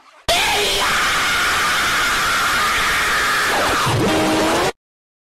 Ear Bleed Extremely Loud Scream